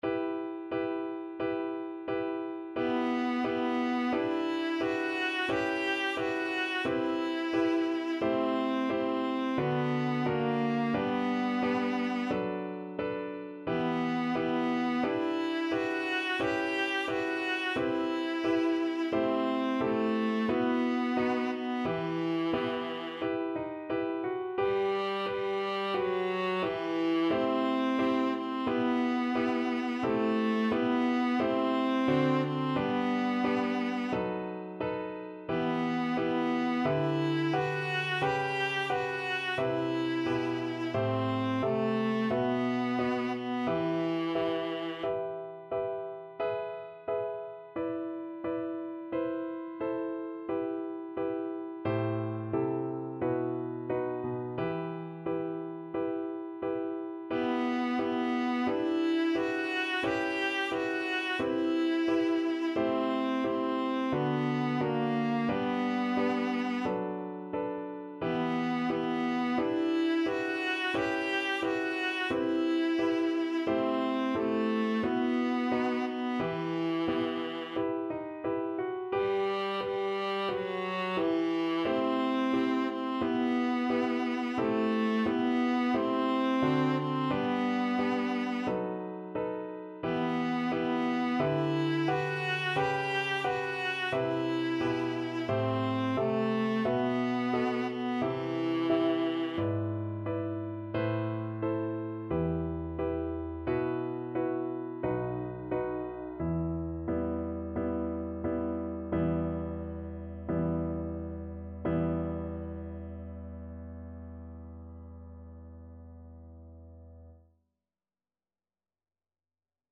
Classical Trad. Kojo no Tsuki Viola version
Viola
E minor (Sounding Pitch) (View more E minor Music for Viola )
4/4 (View more 4/4 Music)
Andante =c.88
Classical (View more Classical Viola Music)
Japanese